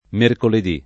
vai all'elenco alfabetico delle voci ingrandisci il carattere 100% rimpicciolisci il carattere stampa invia tramite posta elettronica codividi su Facebook mercoledì [ merkoled &+ ] s. m. — non mercoledi — fam. mercoldì [ merkold &+ ]; antiq. mercordì [ merkord &+ ]